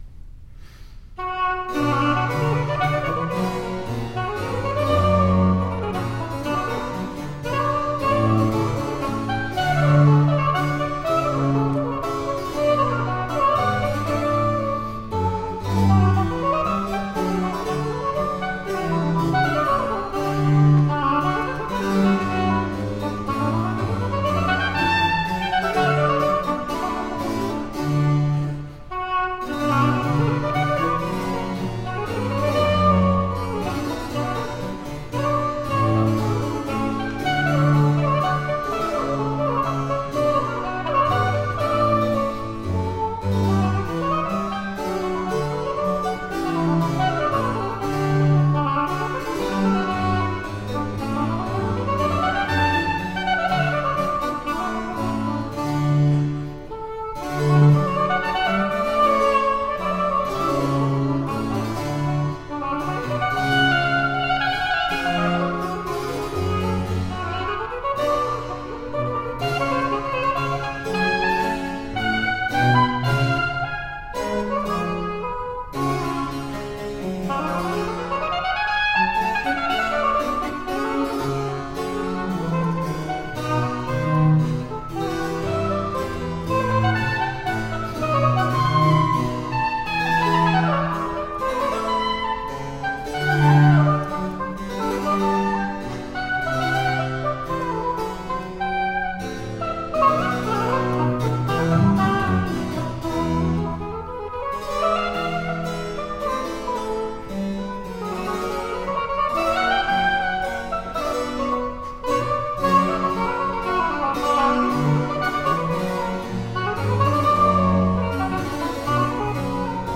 Baroque oboist extraordinaire.
Classical, Chamber Music, Baroque, Instrumental, Cello
Harpsichord, Oboe